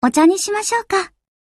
Ship Voice Houshou Improvement.mp3
Ship_Voice_Houshou_Improvement.mp3.ogg